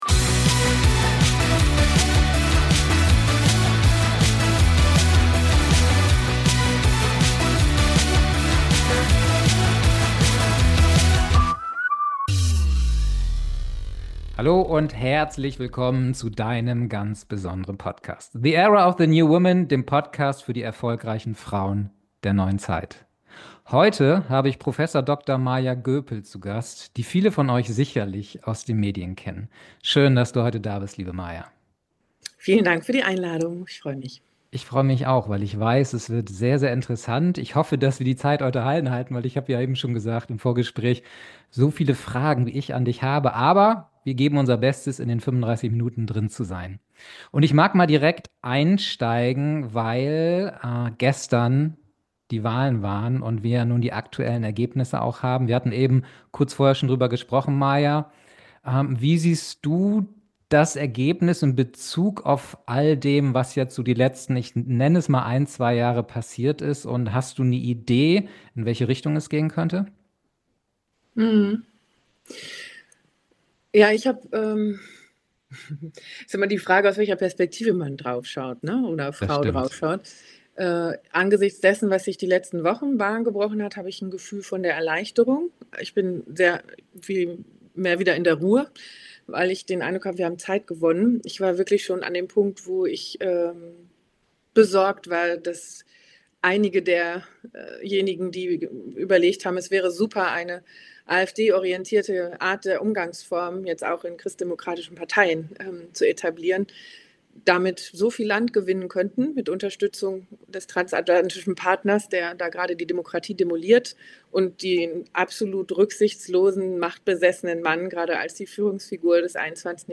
#047 Schaffen wir den Demokratiewandel? Das Interview mit Prof. Dr. Maja Göpel.